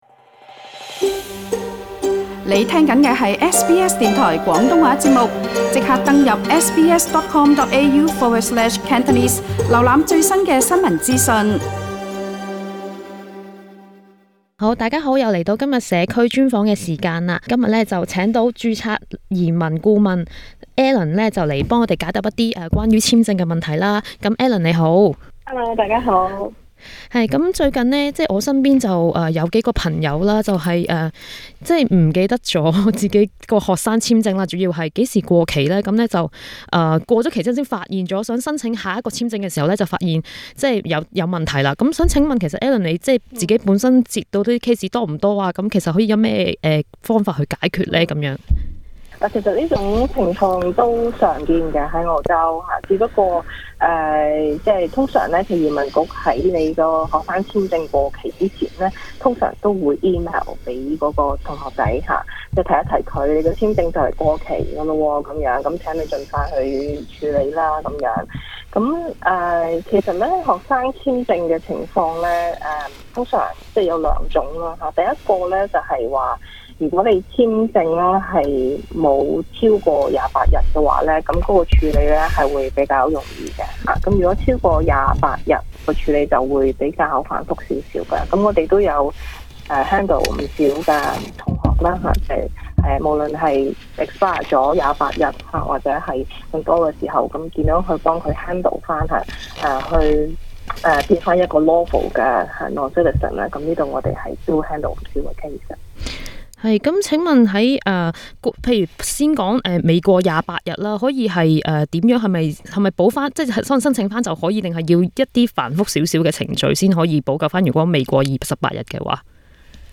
【社區專訪】雪梨義工組織SouperStar : 愛心湯送暖行動